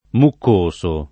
muccoso [ mukk 1S o ]